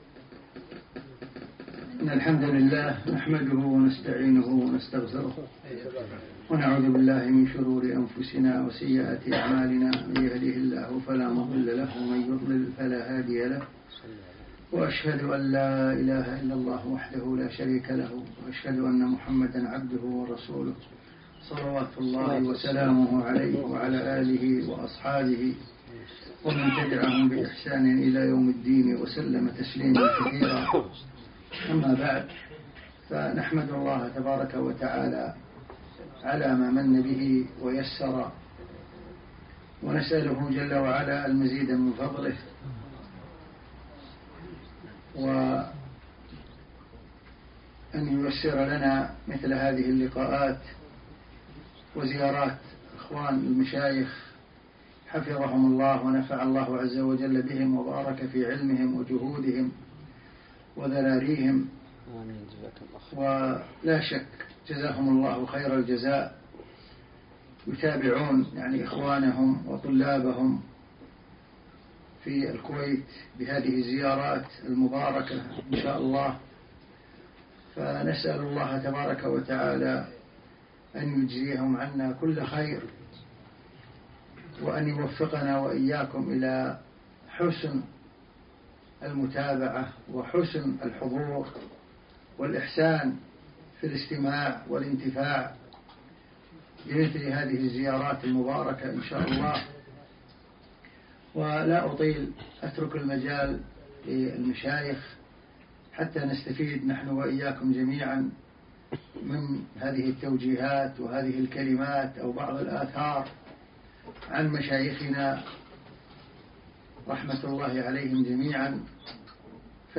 لقاء مفتوح